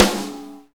nightcore-kick.mp3